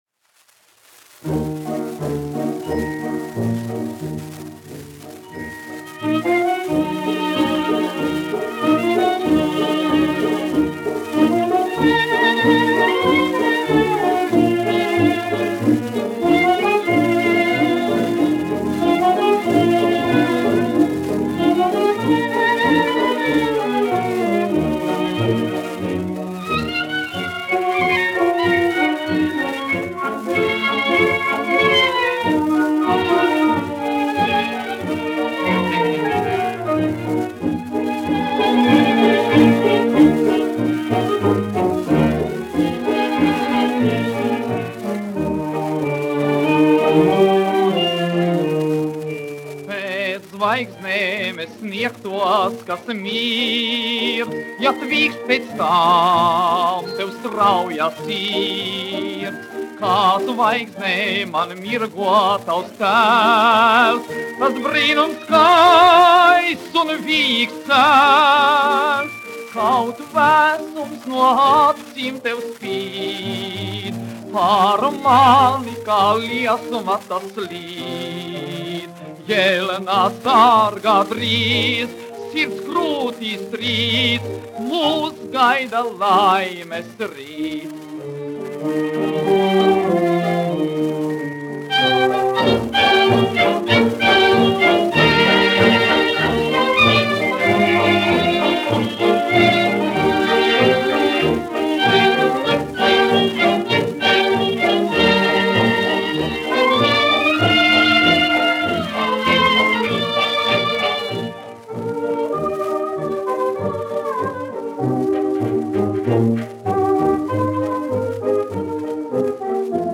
1 skpl. : analogs, 78 apgr/min, mono ; 25 cm
Fokstroti
Populārā mūzika
Latvijas vēsturiskie šellaka skaņuplašu ieraksti (Kolekcija)